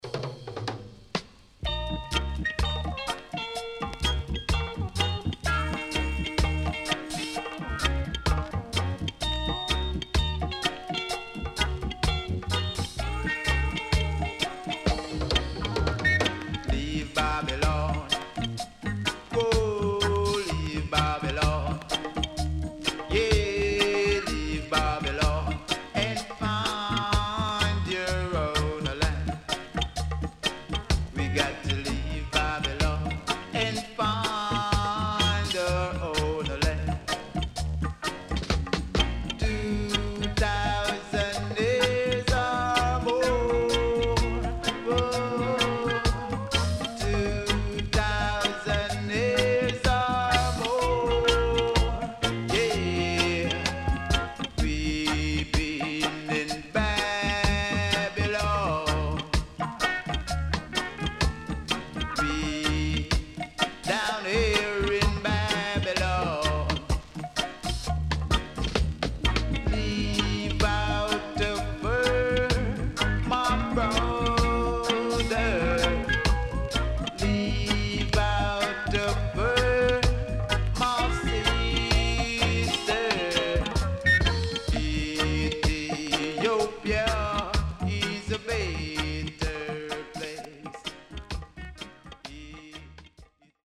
HOME > LP [VINTAGE]  >  KILLER & DEEP  >  RECOMMEND 70's
SIDE A:少しチリプチノイズ入りますが良好です。
SIDE B:少しチリプチノイズ入りますが良好です。